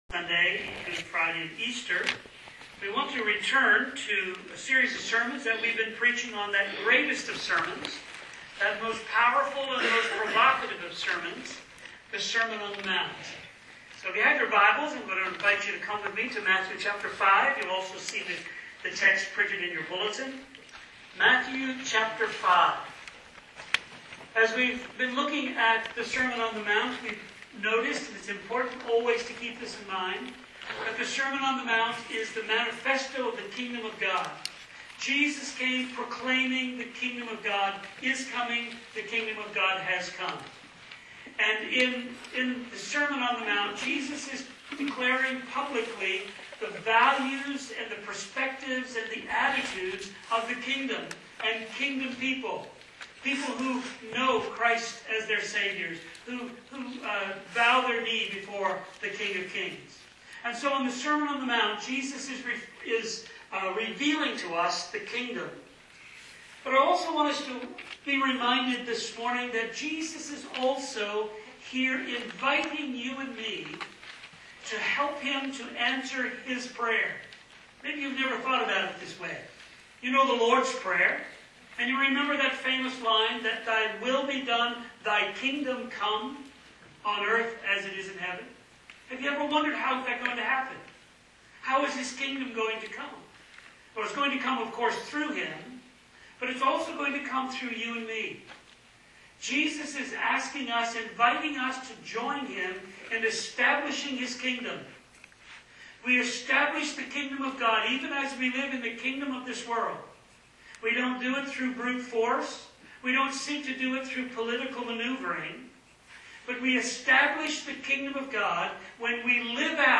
Letter-vs.-Spirit-of-the-Law-Sermon-on-the-Mount-Series.mp3